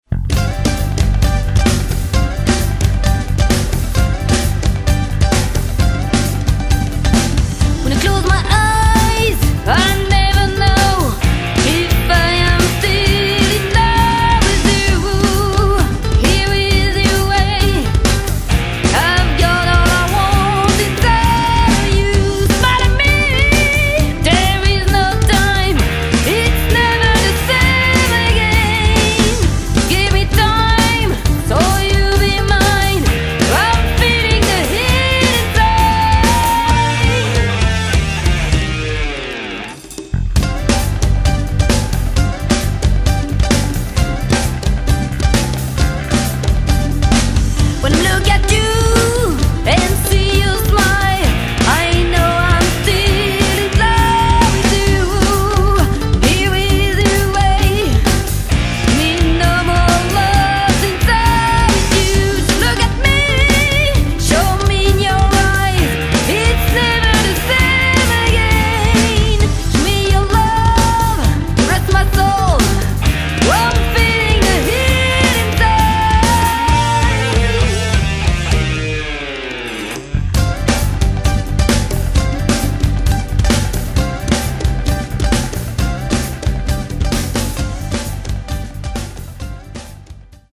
Enregistrement Studio BBM Lausanne